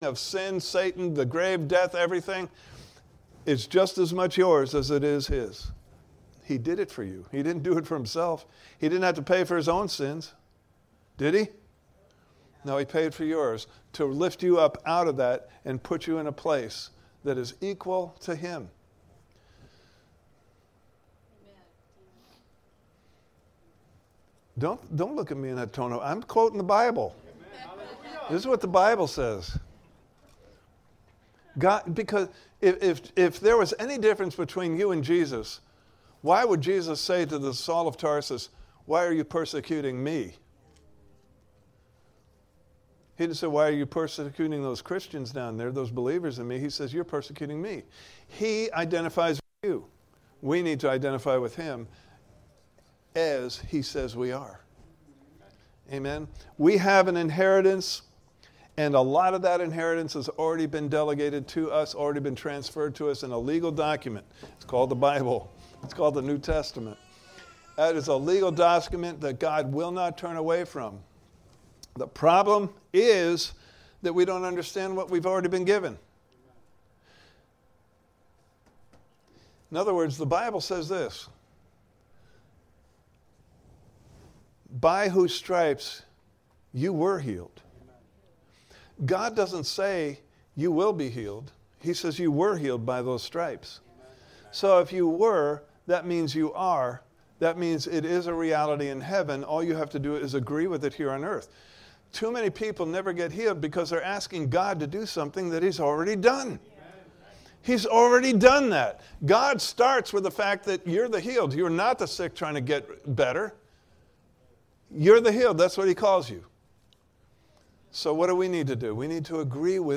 The Inheritance of the Saints Service Type: Sunday Morning Service « Part 4